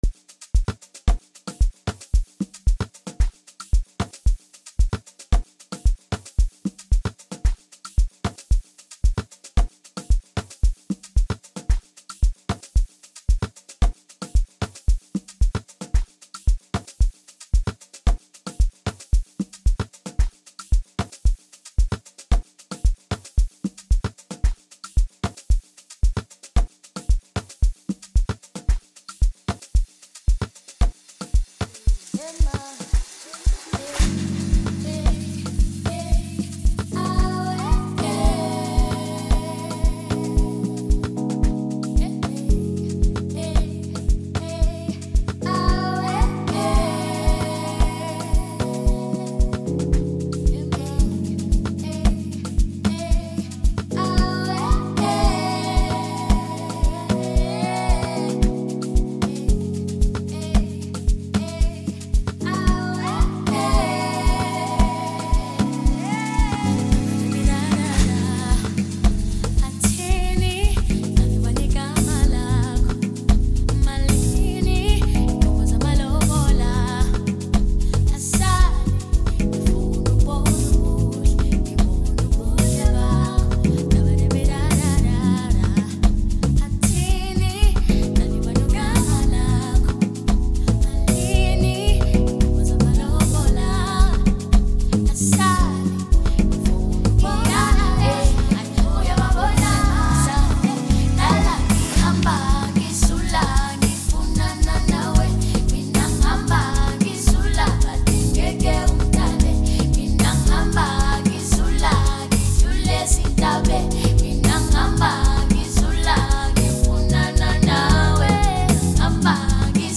Home » DJ Mix » Amapiano » Hip Hop
producing a smooth and impressive final sound throughout.